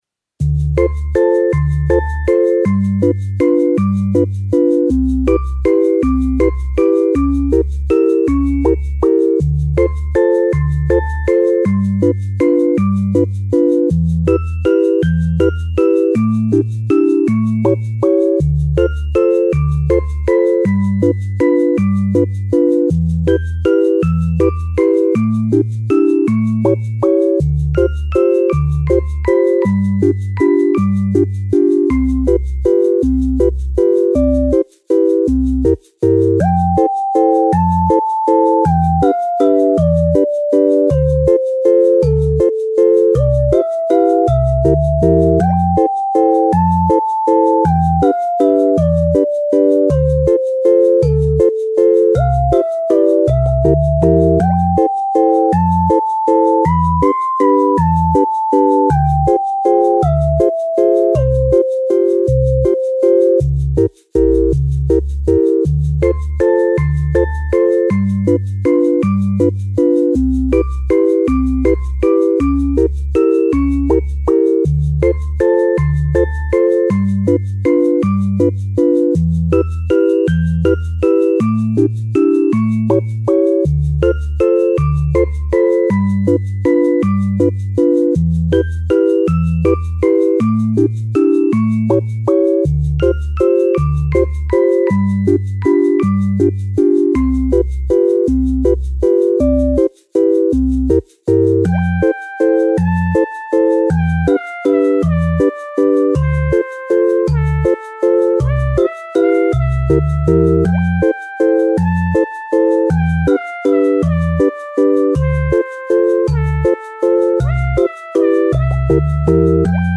ポップ
かわいい ほのぼの ぽこぽこ まったり落ち着く チル ピコピコ 日常
優しくてかわいい雰囲気の楽曲です🐇🐈🍞 作業用,配信用の他にも料理Cooking用にもどうぞ